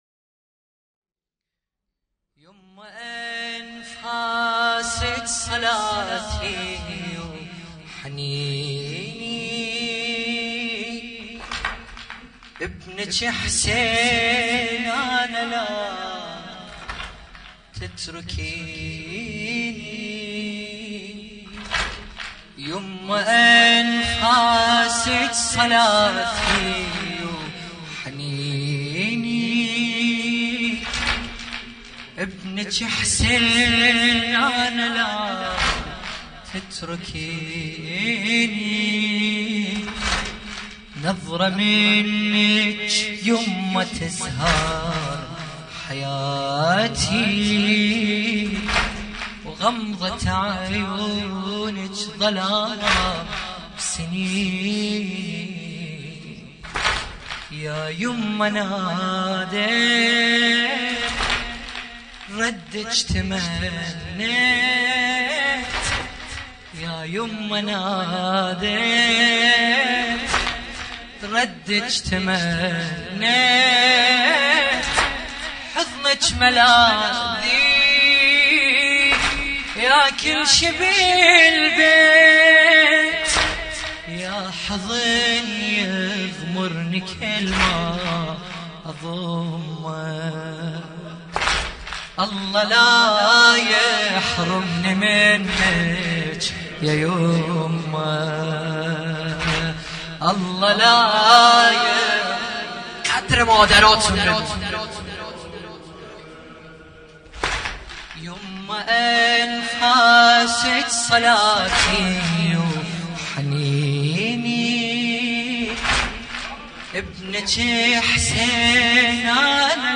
سنگین (عربی) | الله لا يحرمنی منج يا يمه
مراسم عزاداری شب های فاطمیه اول